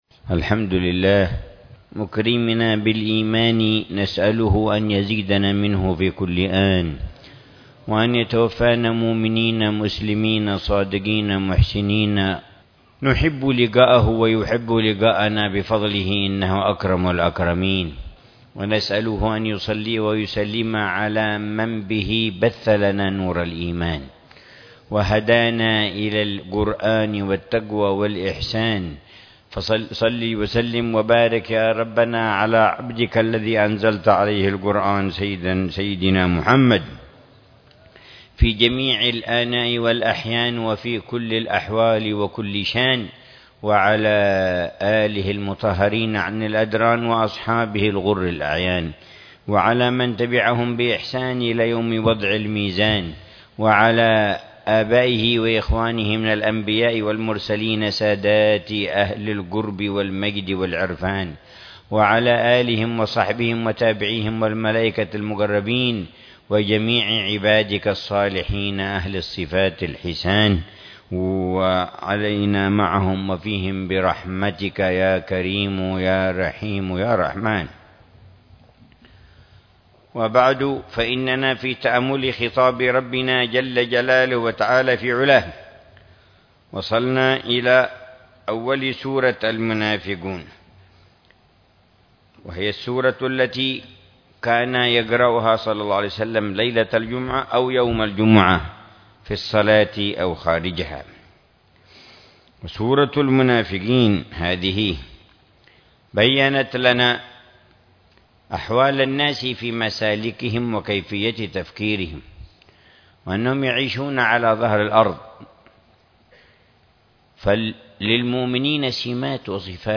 تفسير الحبيب العلامة عمر بن محمد بن حفيظ للآيات الكريمة من سورة المنافقون، ضمن الدروس الصباحية لشهر رمضان المبارك لعام 1441، من قوله تعالى: {